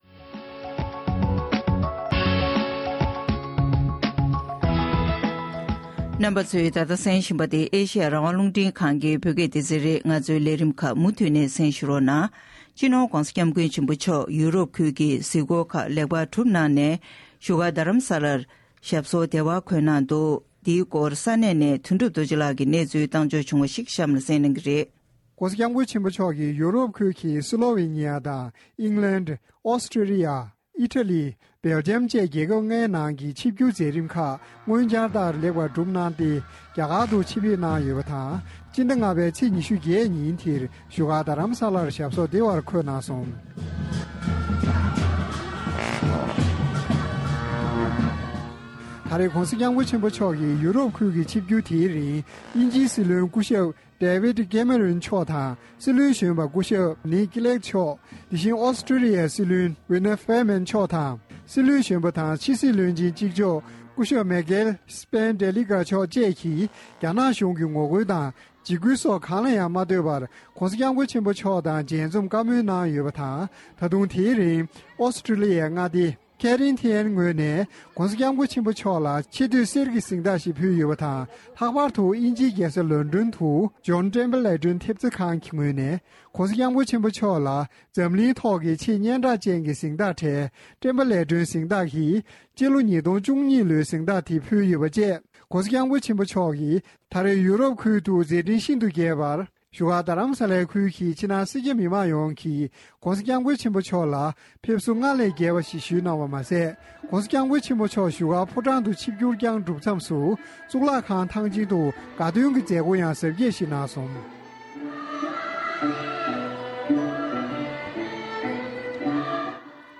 ས་གནས་ས་ཐོག་ནས་བཏང་འབྱོར་བྱུང་བའི་གནས་ཚུལ་ཞིག